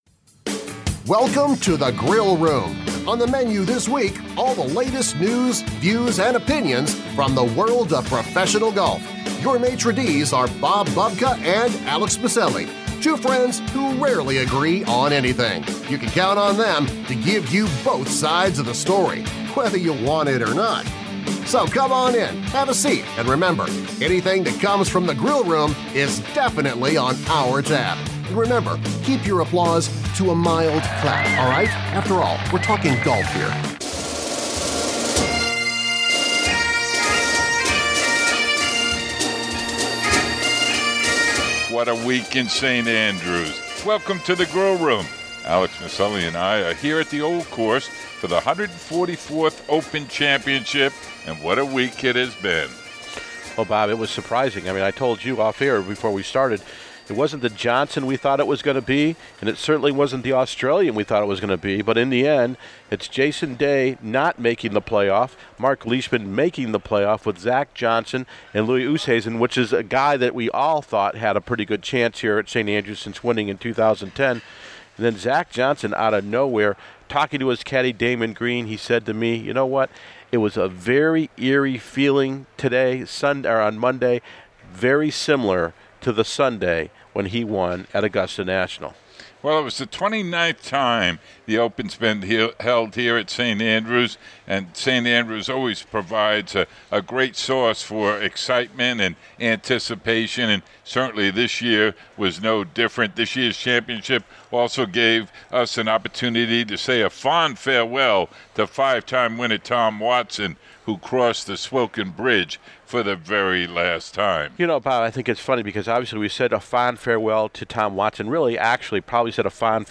The Feature Interview